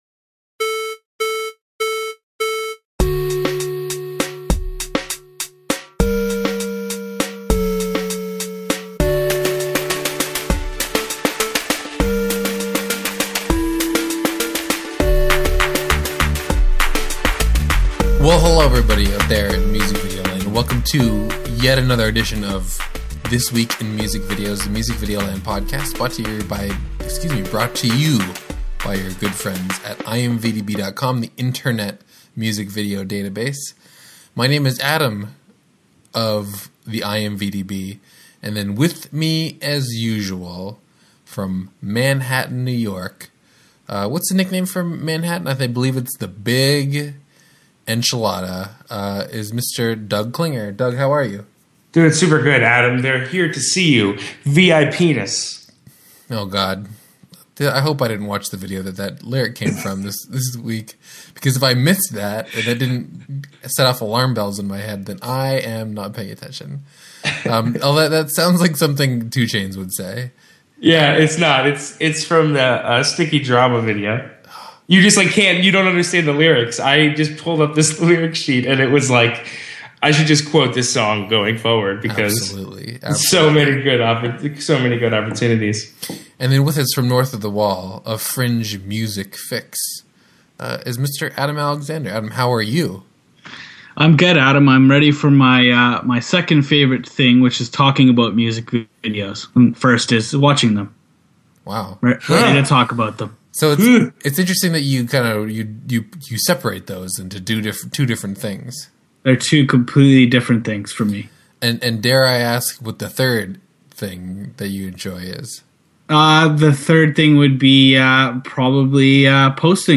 A weekly conversation about music video news and new releases.